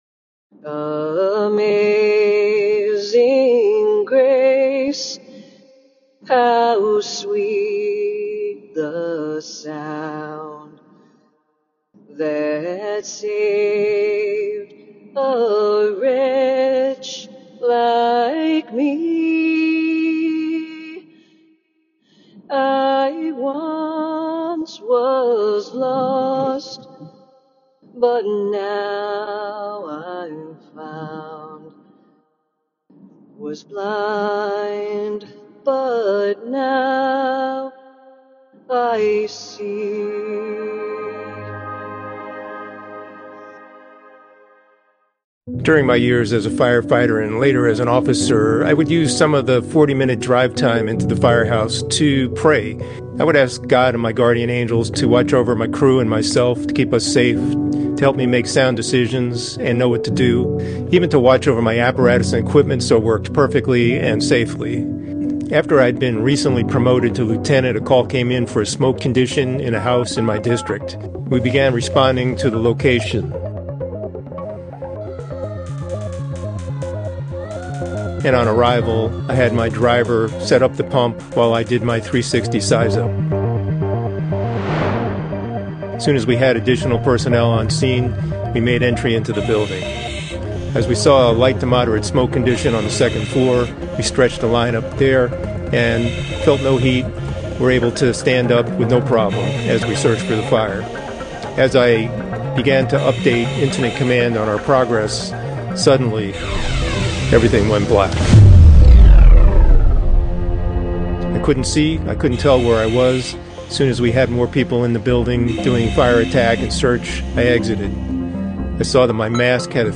We will also hear what Spirituality means to those who risk their lives to protect, and in some cases, to save OUR lives. We will learn how their beliefs help them through their darkest moments.